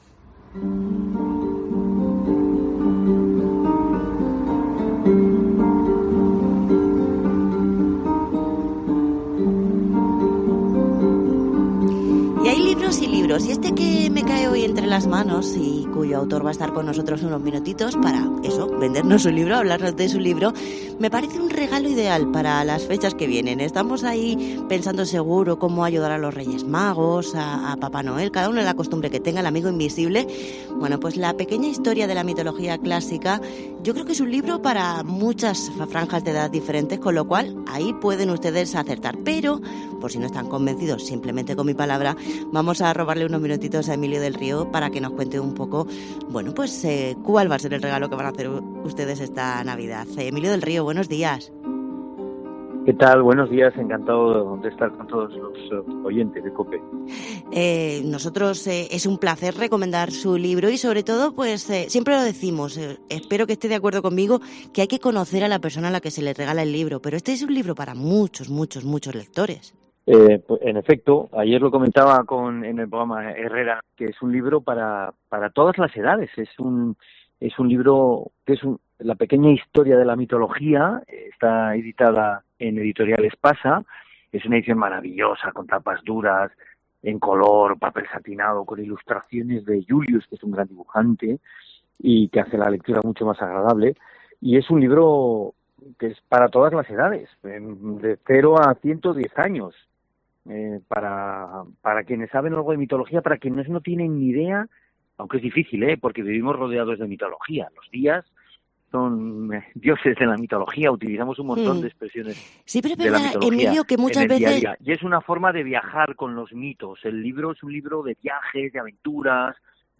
Entrevista a Emilio del Río con su libro de mitología